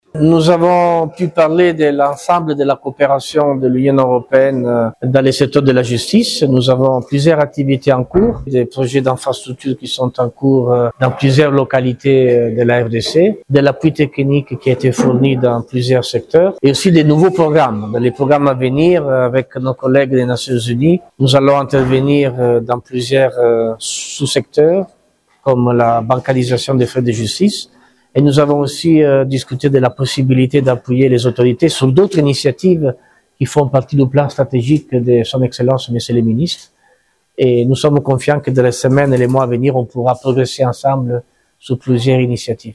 Le chargé d’affaires Antonio Capone est revenu sur la quintesence de ces discussions à l’issue de l’audience, Antonio Capone.